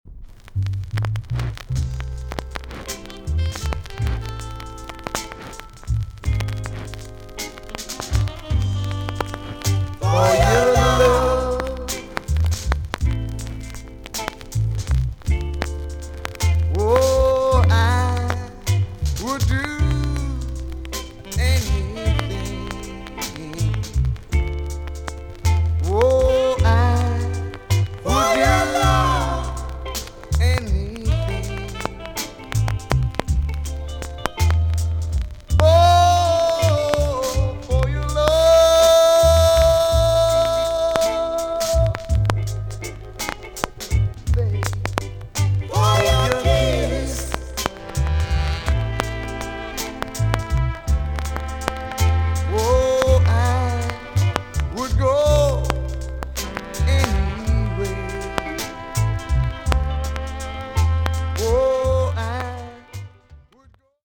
TOP >SKA & ROCKSTEADY
VG ok 全体的にプチノイズが入る箇所があります。